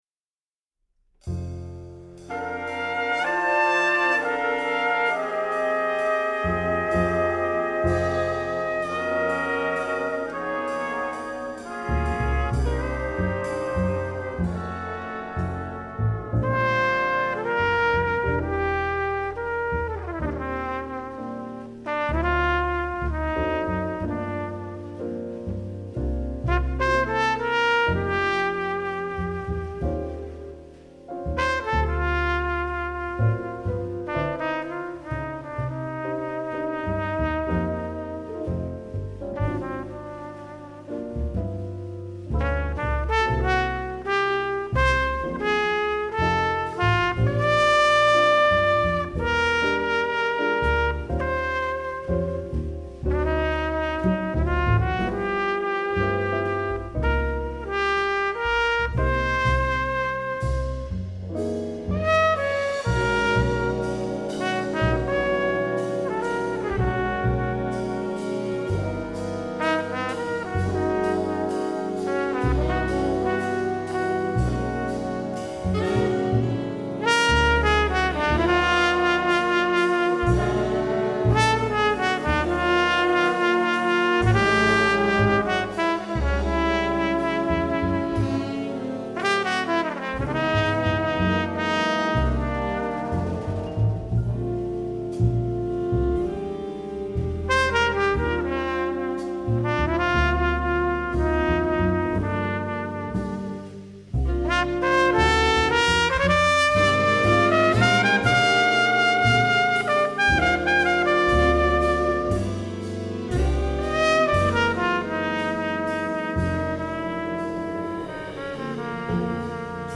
Store/Music/Big Band Charts/ORIGINALS/PORTRAIT
Doubles: flutes, clarinets, bass clarinet
Solos: trumpet and vocal feature